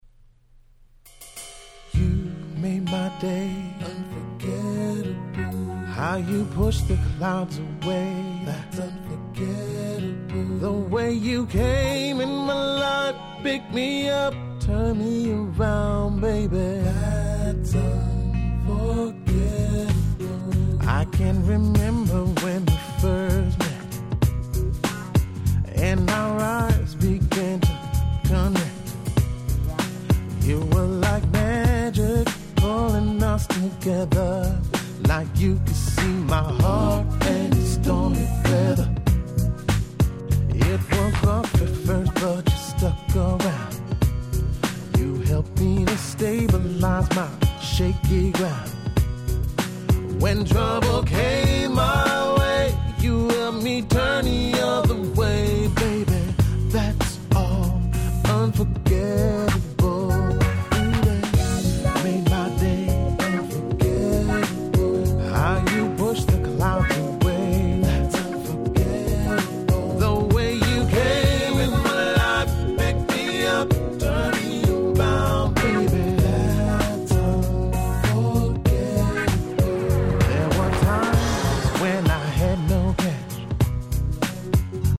04' Nice R&B !!
Smoothだし歌唱力あるしでこれじゃ海外のマニアが欲しがるのも頷けます。